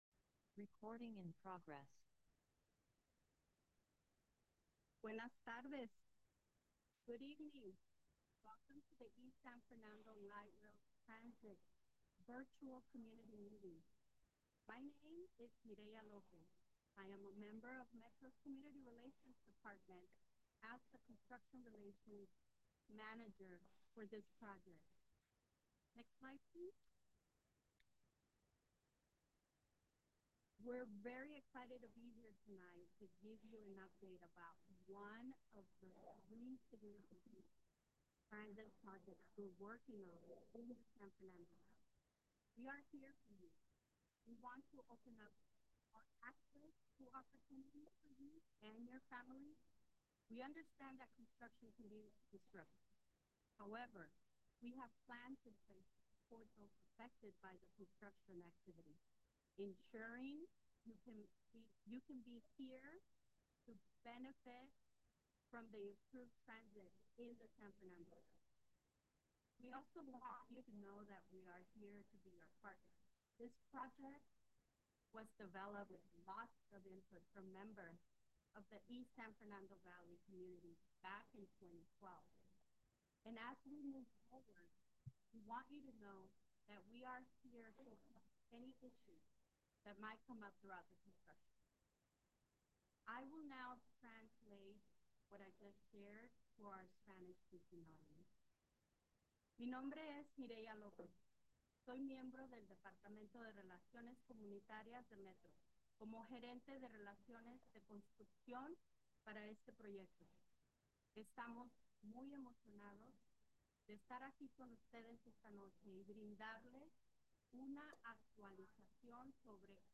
2024_11_7_CommunityMeeting_Spanish.m4a